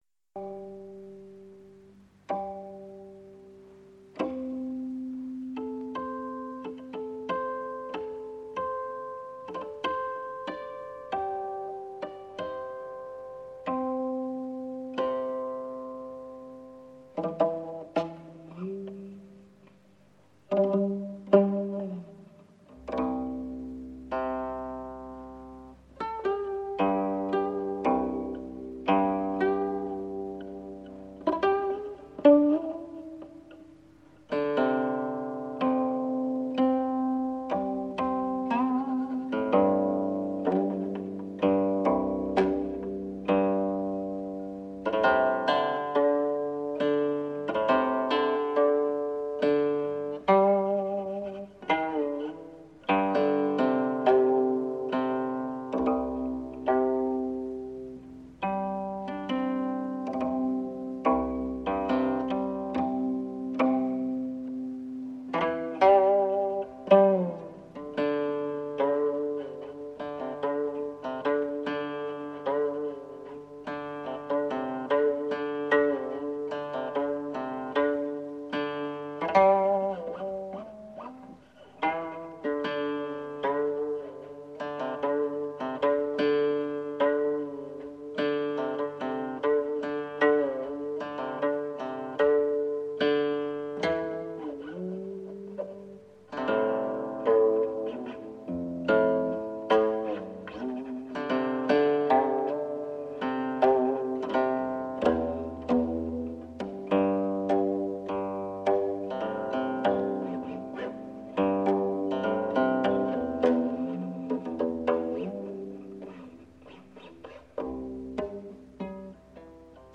乐曲慷慨激昂气势宏伟，为古琴著名大曲之一。